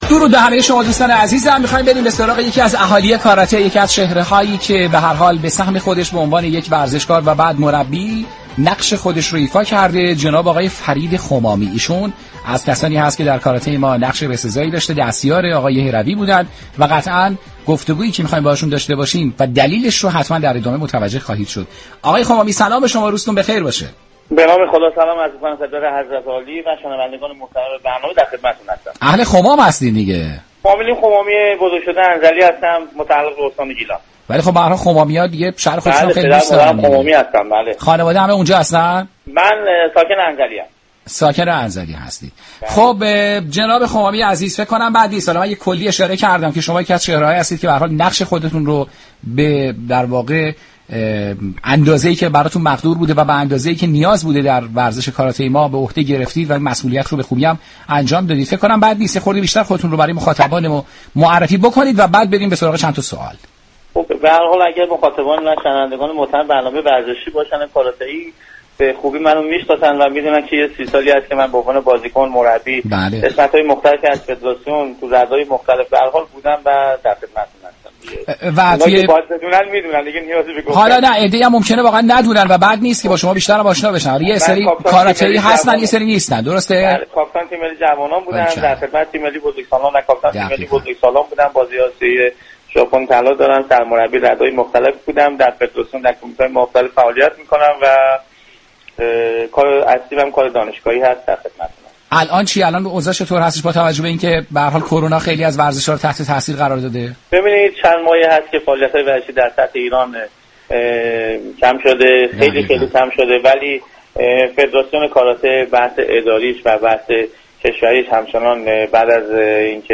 مصاحبه كامل